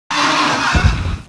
Heroes3_-_Gold_Dragon_-_DeathSound.ogg